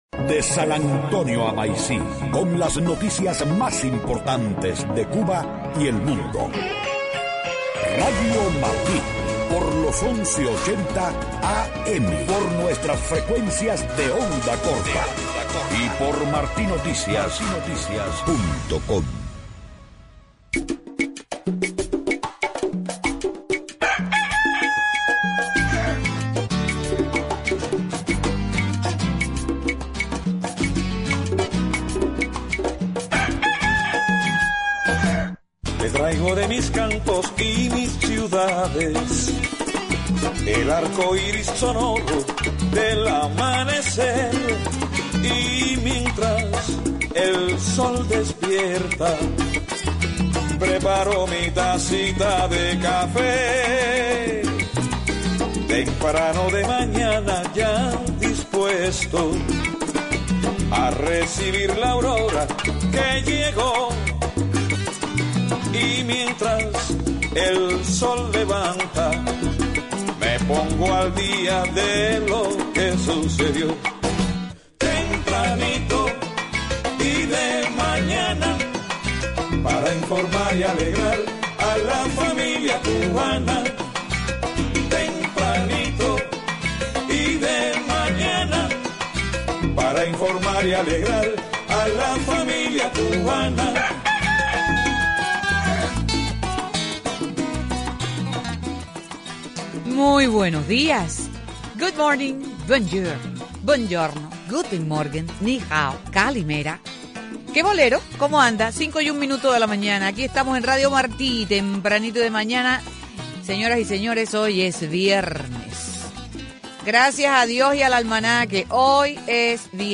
Una interesante entrevista